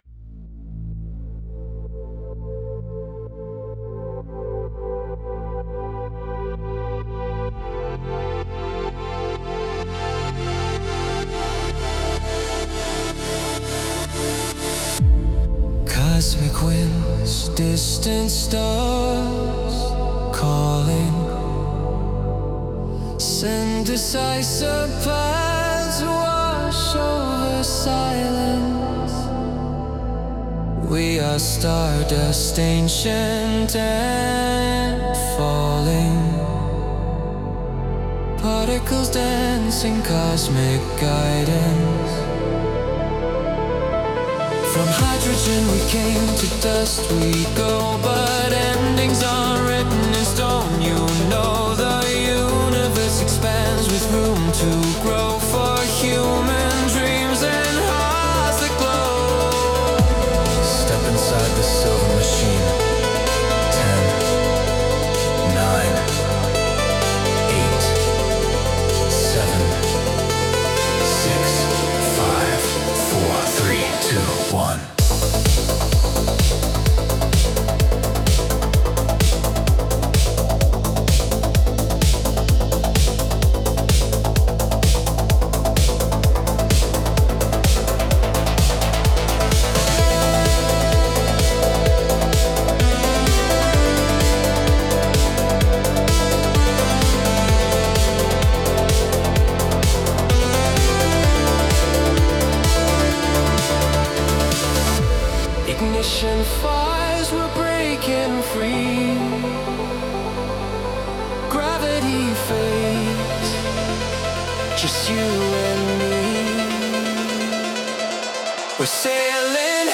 Electronic • Sci-Fi • Atmospheric • Exploration